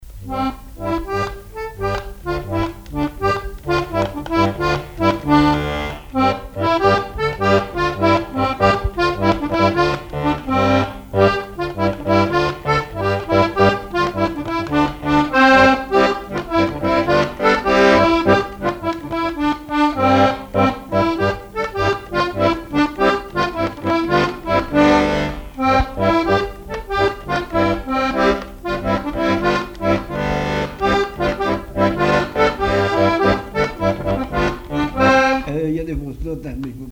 danse-jeu : trompeuse
Chansons et répertoire du musicien sur accordéon chromatique
Pièce musicale inédite